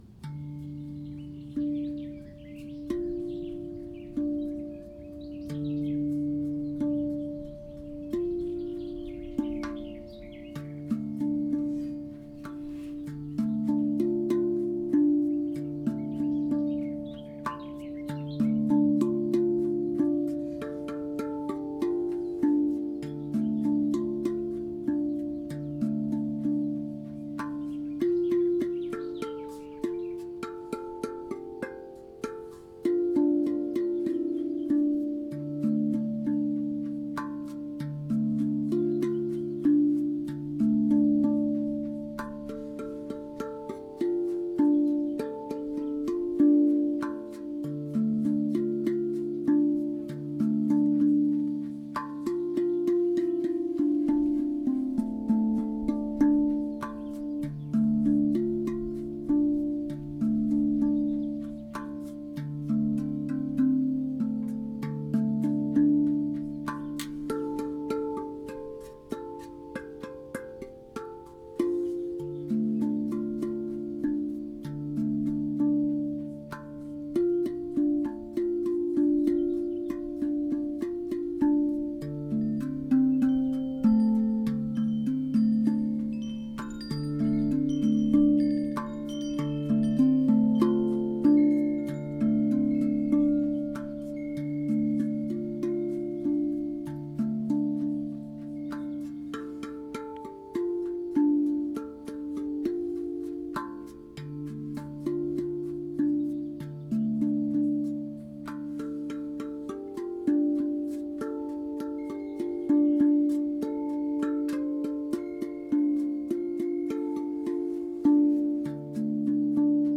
Die Musik mit der Rav Vast Trommel ist übrigens im Garten gestern aufgenommen worden. Windspiel-Geklimper und Vogelgezwitscher im Hintergrund…